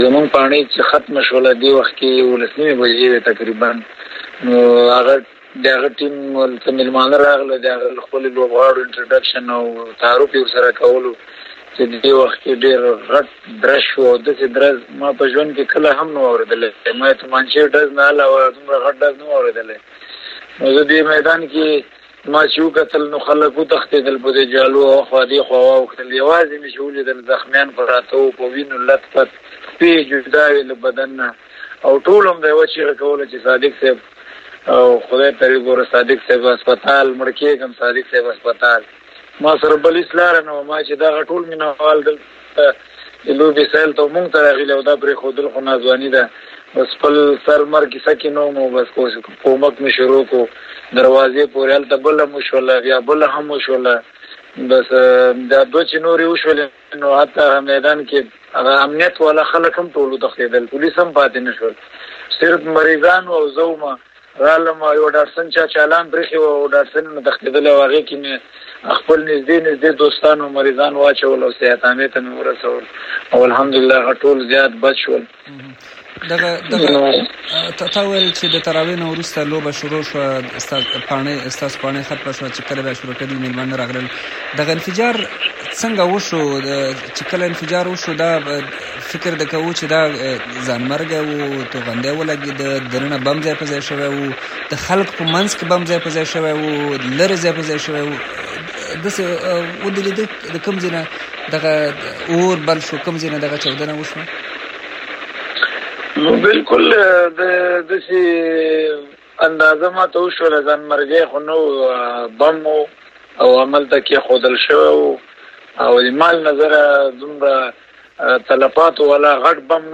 ښاغلي صادق امریکا غږ سره په یو ځانګړې مرکې کې وویل چې داسې چاودنې یې په ژوند کې نه وې لېدلې او لا هم فکر کوي‌ چې چا او ولې د کرکټ پر میدان او ولسي خلکو برید کړی دی.